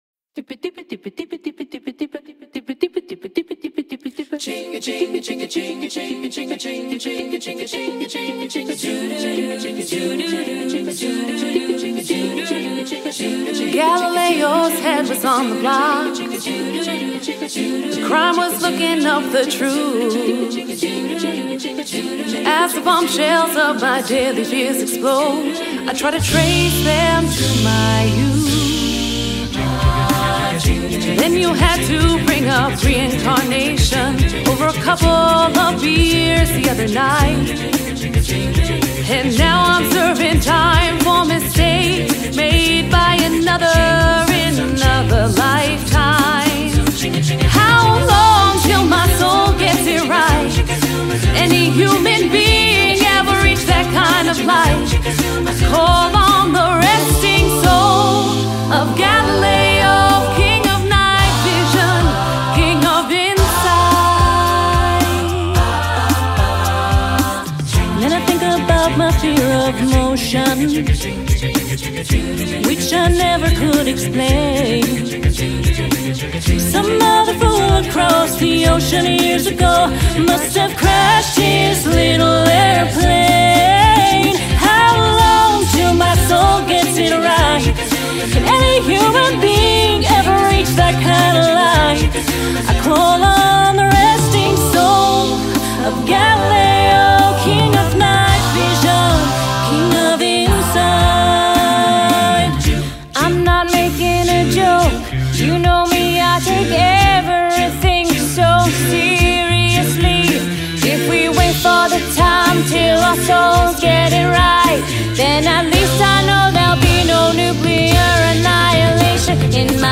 Genre: Pop
Contains solos: Yes